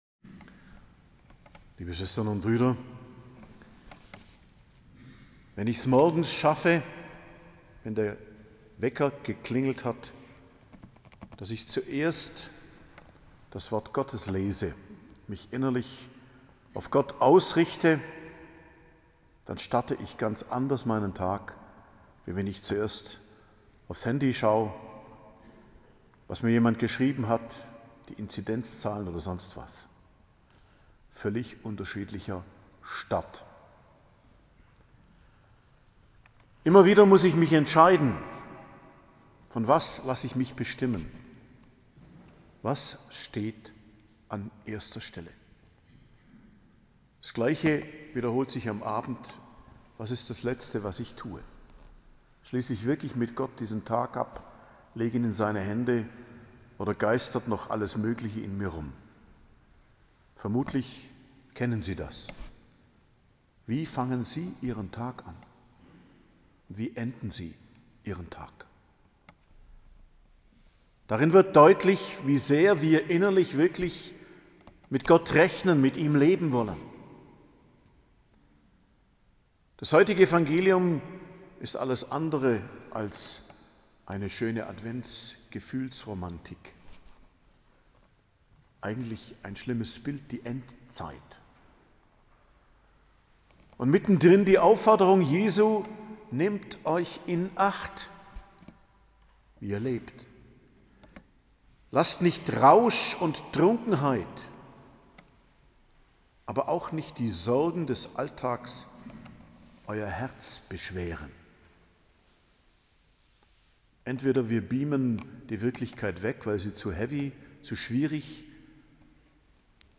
Predigt zum 1. Adventssonntag, 28.11.2021